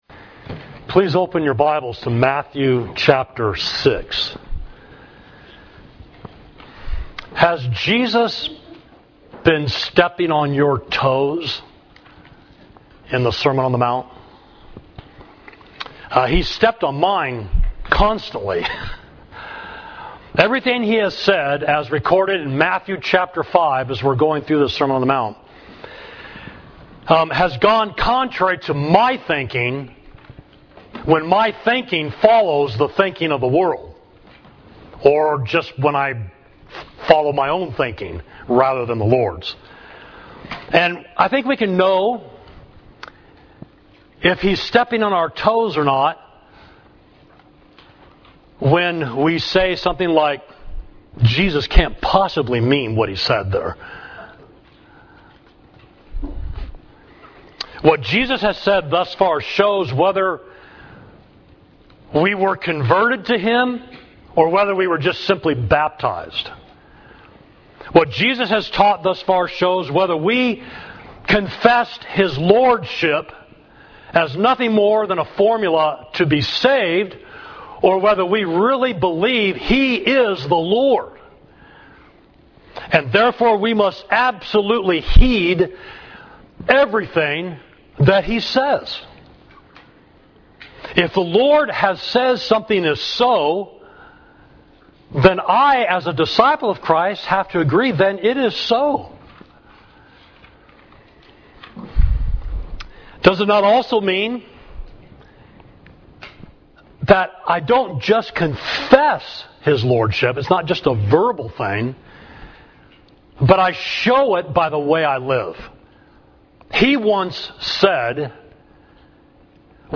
Sermon: Practice Righteousness Sincerely